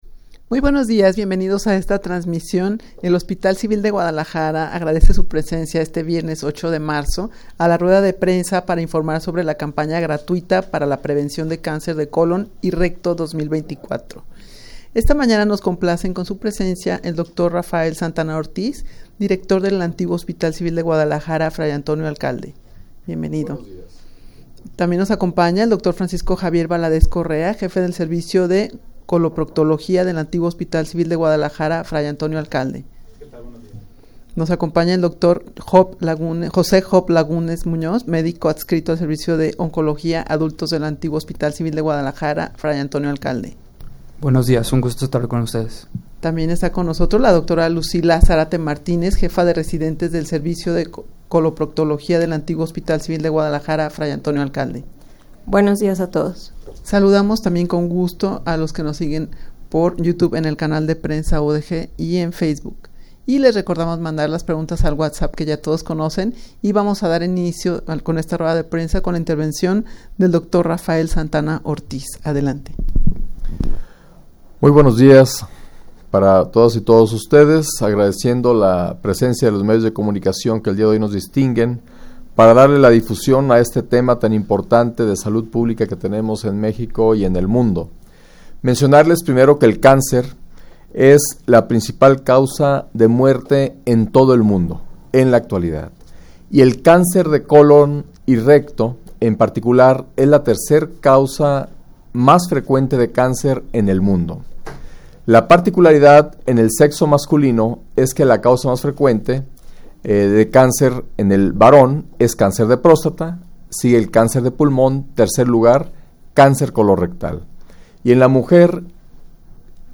Audio de la Rueda de Prensa
rueda-de-prensa-para-anunciar-campana-gratuita-para-la-prevencion-de-cancer-de-colon-y-recto-2024.mp3